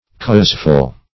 Causeful \Cause"ful\, n. Having a cause.